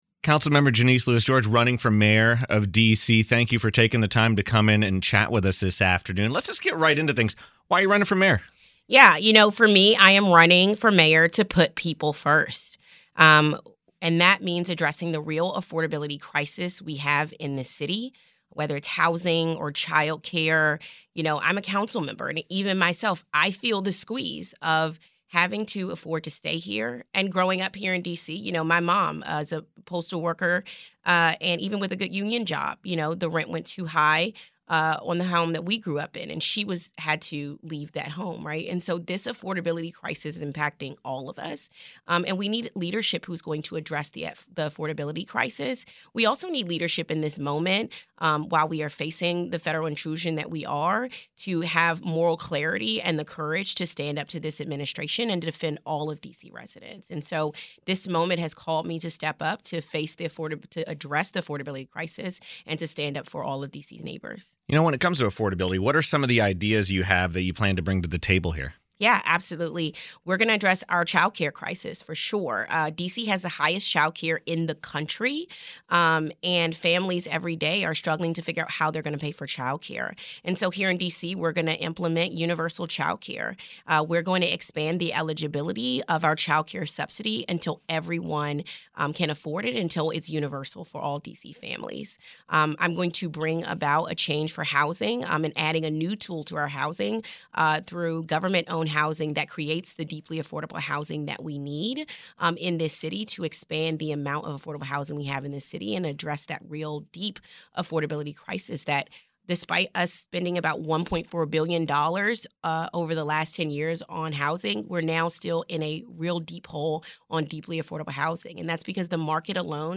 LEWIS-GEORGE-INTERVIEW.mp3